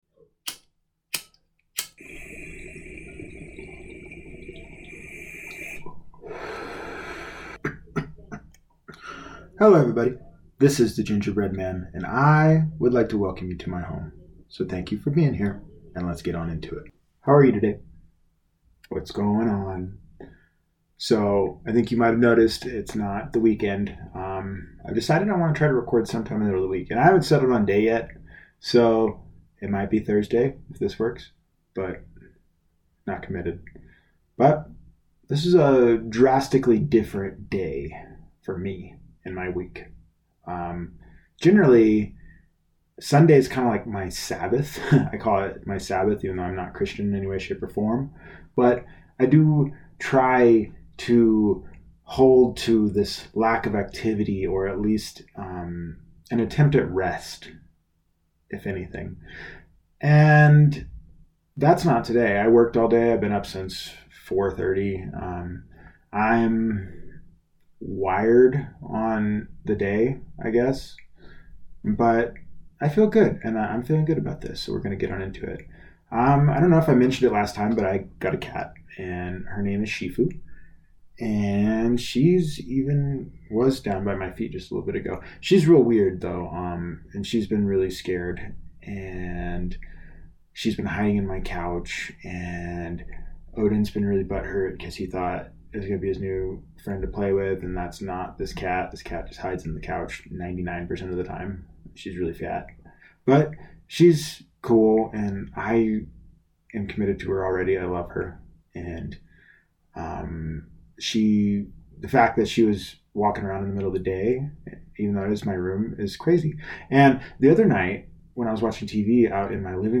Towards the end of the podcast I do a reading from Alan Watt's "The Joyous Cosmology", what a beautiful speaker that man was.